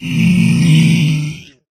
zpighurt.ogg